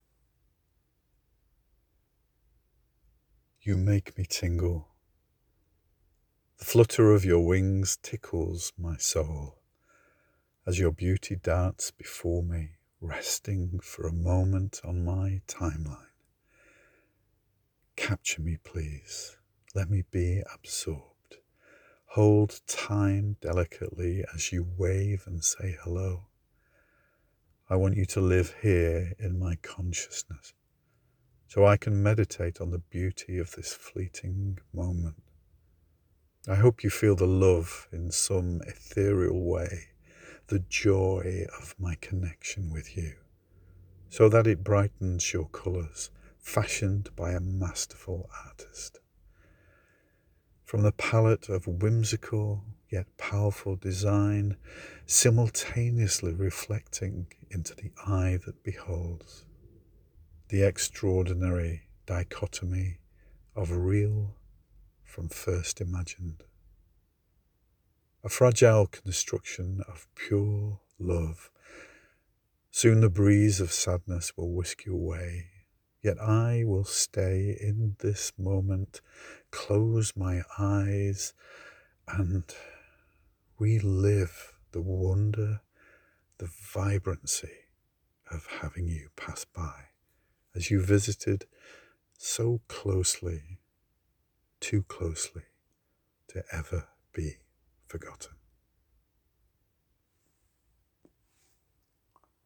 And you have a great reading voice.
Your narration brings the words to life as they flutter from the page so gracefully like butterfly wings.